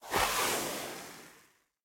slide.ogg